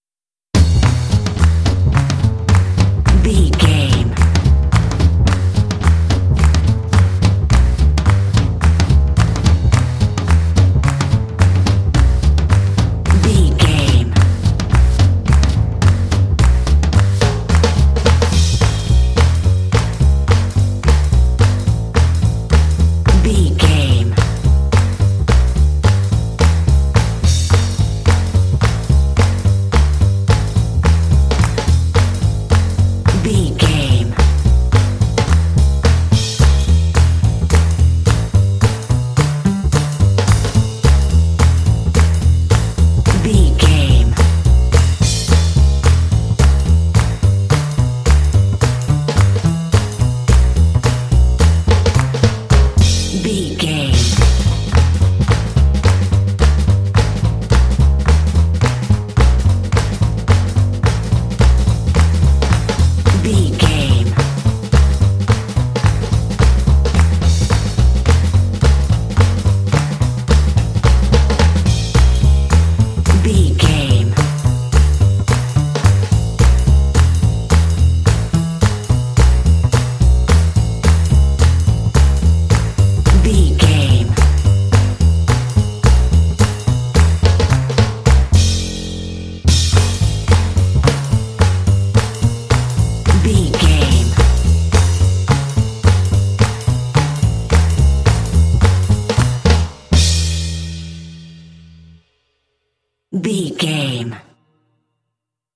Uplifting
Mixolydian
Fast
energetic
bouncy
joyful
double bass
drums
big band
jazz